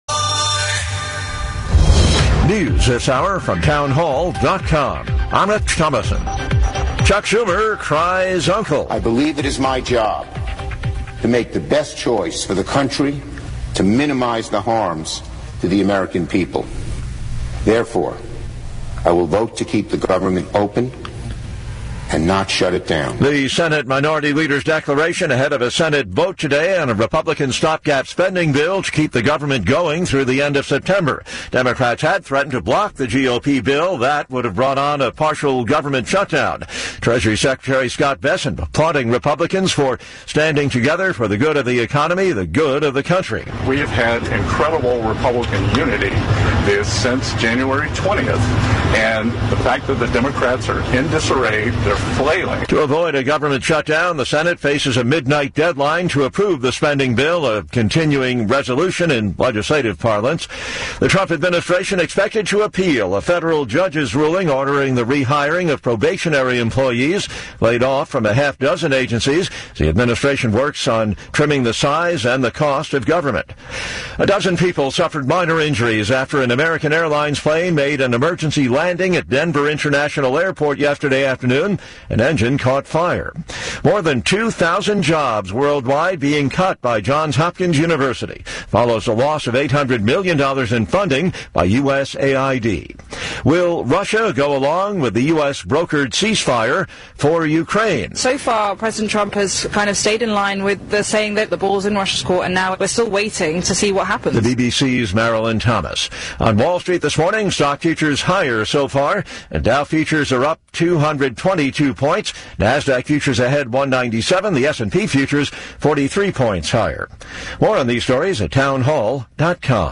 Attack on a Baltimore Co Police officer, power struggle in MD Dem leadership leading to more radical bills, Del. Nino Mangione calls in to discuss the latest in the MD General Assembly and more.